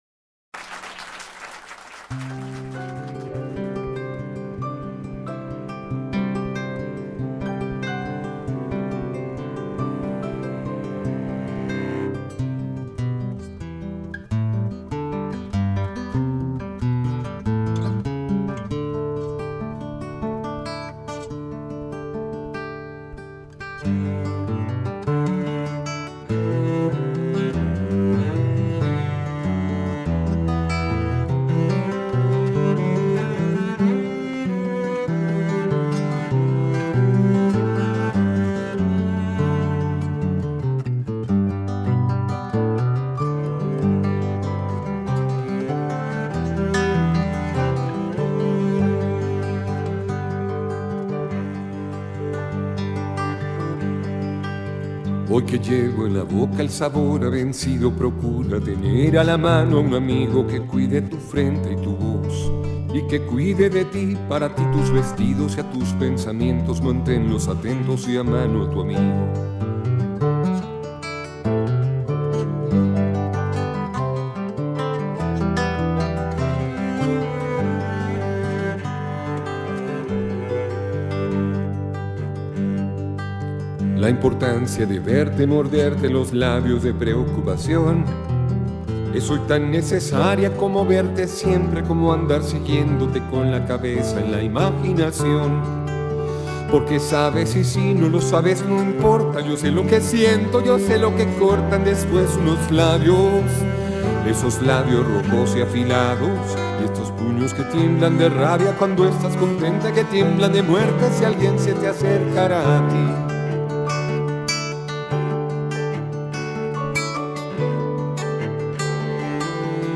Carpeta: Folklore mp3
Version Acustica